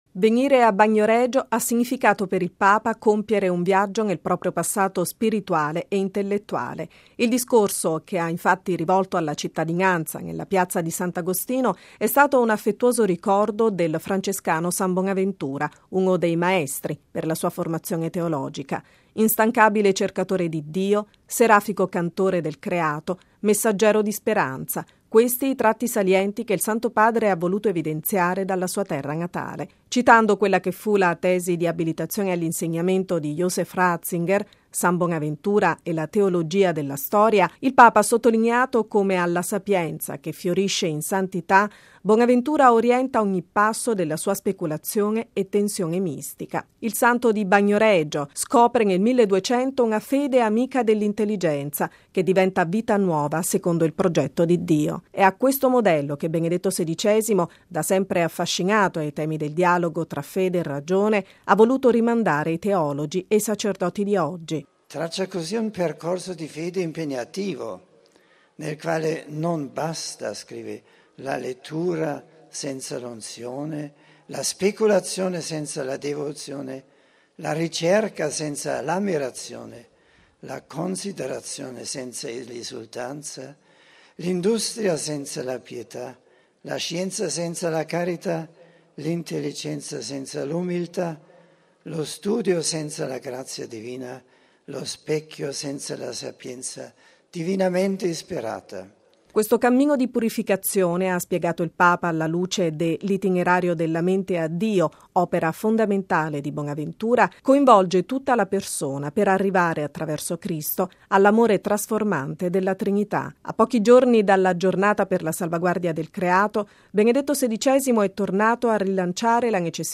Nella concattedrale ha venerato la reliquia del braccio di San Bonaventura e dopo il saluto del sindaco e del vescovo, in Piazza Sant’Agostino ha incontrato la cittadinanza pronunciando un discorso su San Bonaventura. Il servizio